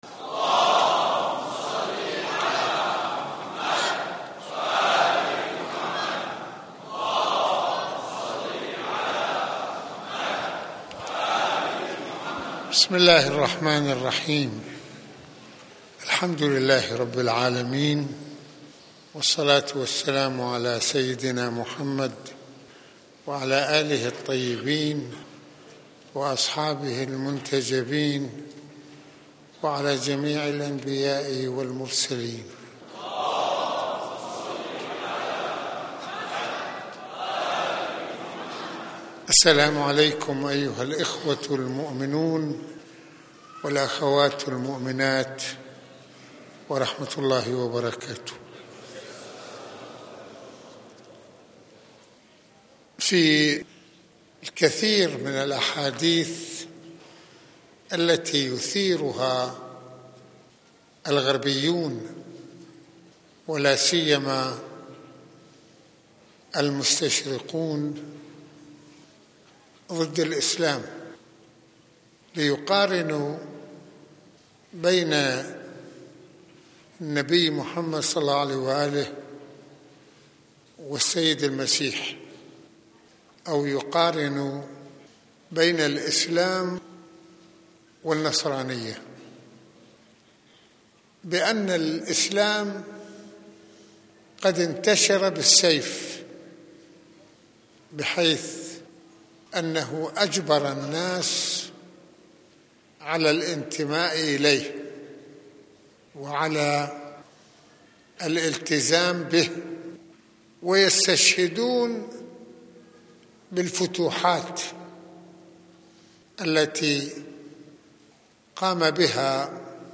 - المناسبة : عاشوراء المكان : مسجد الإمامين الحسنين (ع) المدة : 33د | 31ث المواضيع : المستشرقون والمقارنة بين السيد المسيح وبين النبي محمد (ص) - هل انتشر الاسلام بالسيف ؟ -شرعية الفتوحات الاسلامية التي قام بها بنو امية والعباس